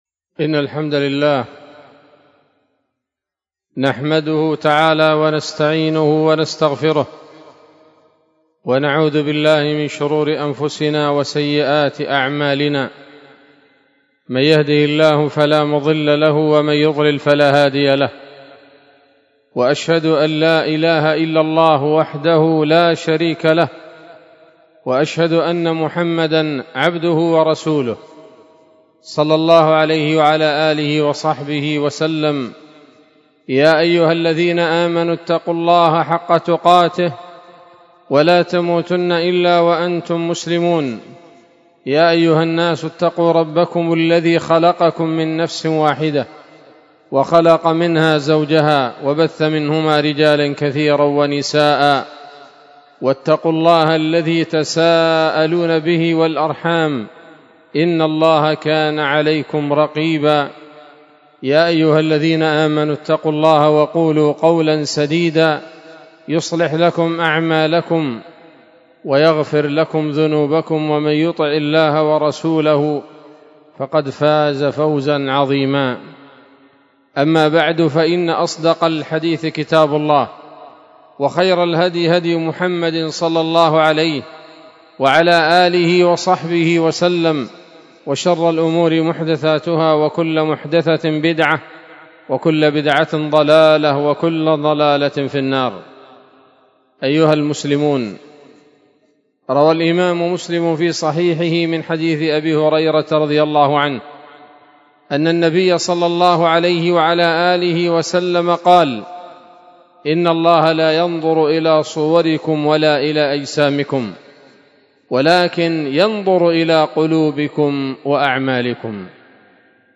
خطبة جمعة بعنوان: (( علاج القلوب )) 8 ربيع الآخر 1446 هـ، دار الحديث السلفية بصلاح الدين